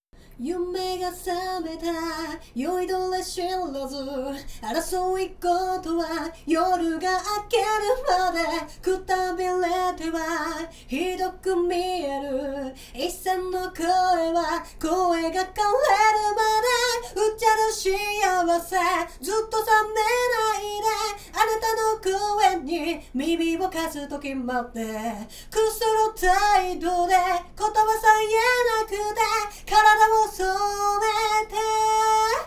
▼こちらが実際にカラオケで収録した素音源(MIX前のもの)です。
MIX師さんにアドバイスをもらう前に収録したものなのですが、①自宅と比べるとかなり反響しているのが分かりますよね。